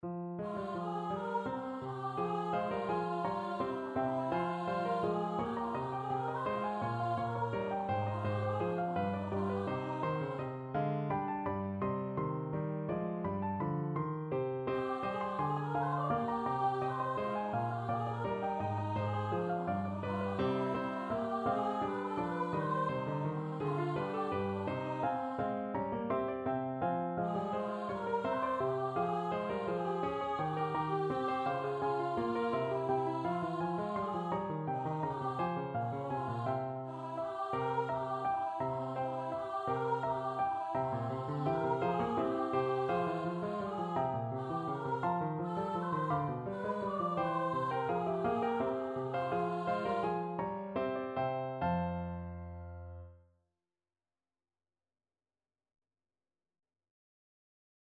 3/8 (View more 3/8 Music)
Classical (View more Classical Contralto Voice Music)